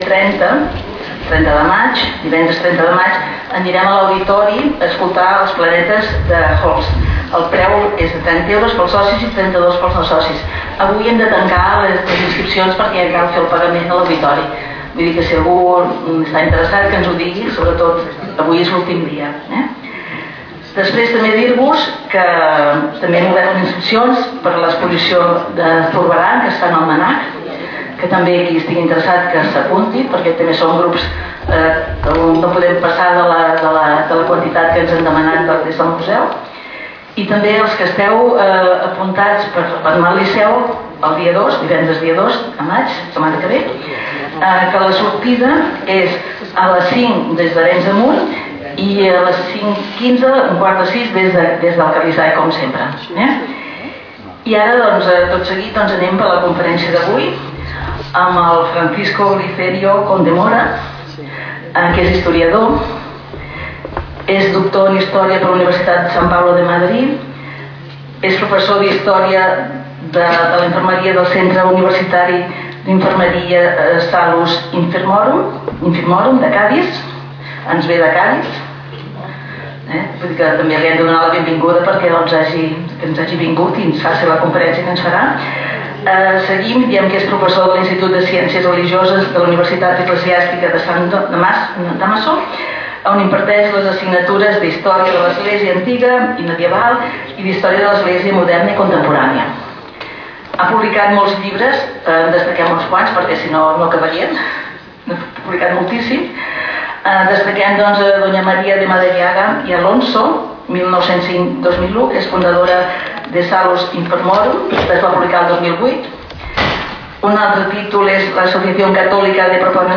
Lloc: Sala d'actes del Col.legi La Presentació
Categoria: Conferències